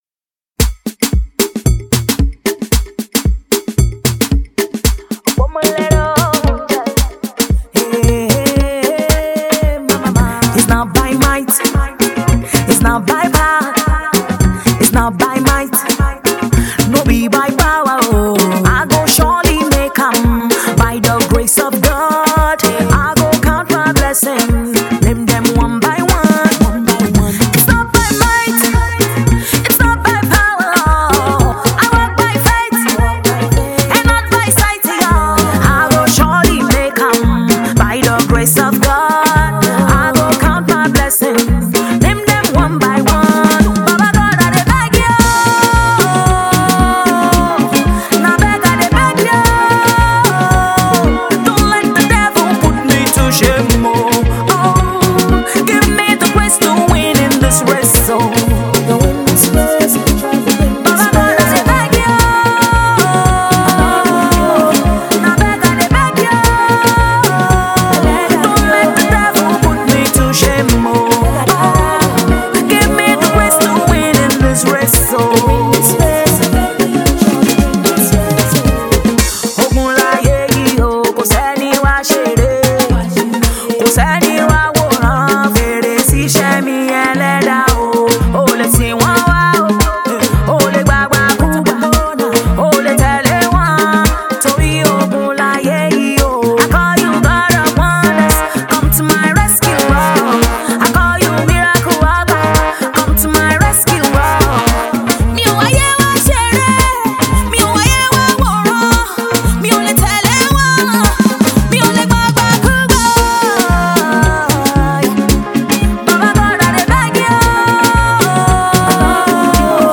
a gospel music artiste
trendy groovy track